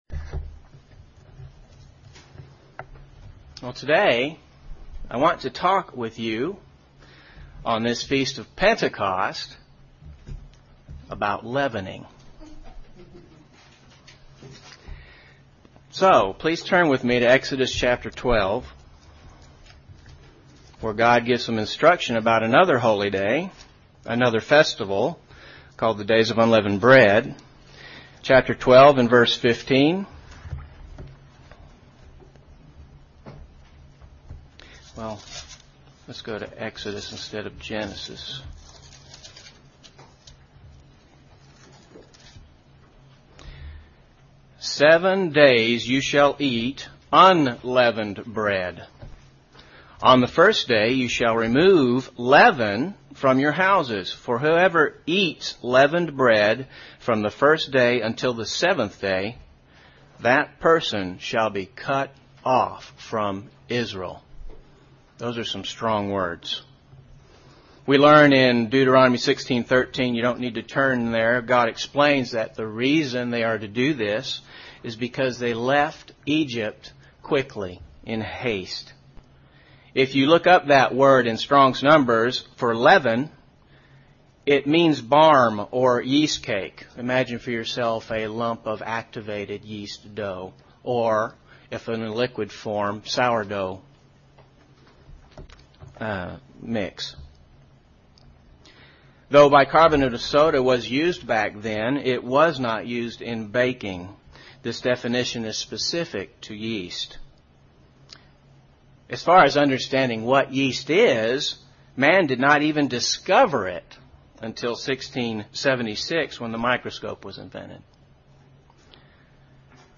UCG Sermon The Feast of Pentecost the leavening process What is the Kingdom of God?